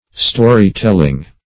Search Result for " story-telling" : The Collaborative International Dictionary of English v.0.48: Story-telling \Sto"ry-tell`ing\, a. Being accustomed to tell stories. -- n. The act or practice of telling stories.